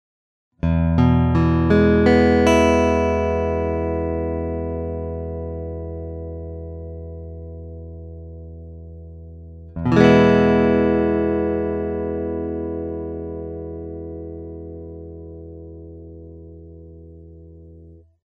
Вот некоторые звуковые файлы (ничего особенного, просто арпеджио ми мажор сопровождаемый мажорным аккордом) с моим Macassar Ebony T5 в различных положениях (от 1 до 5):
Это прямо из гитары в мой 4-х дорожечный рекордер Fostex CompactFlash.